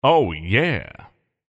voice_tier2_ohyeah.mp3